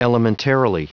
Prononciation du mot : elementarily
elementarily.wav